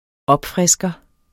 Udtale [ ˈʌbˌfʁεsgʌ ]